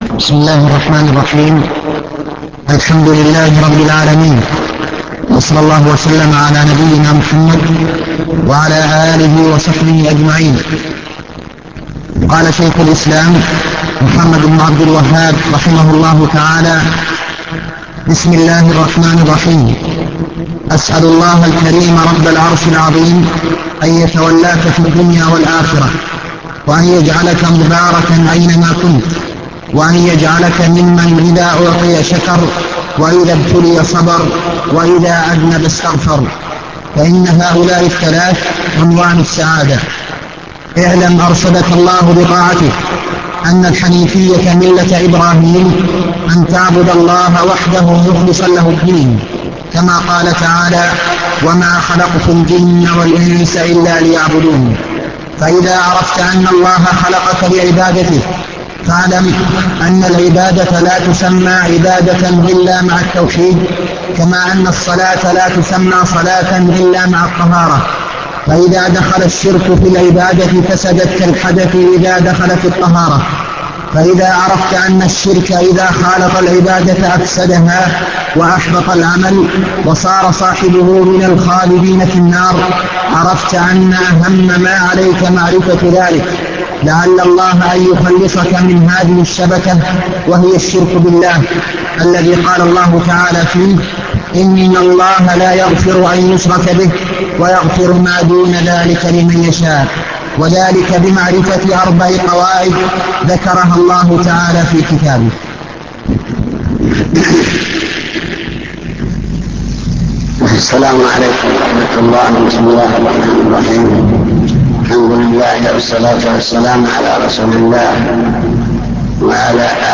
المكتبة الصوتية  تسجيلات - كتب  شرح القواعد الأربعة مقدمة الكتاب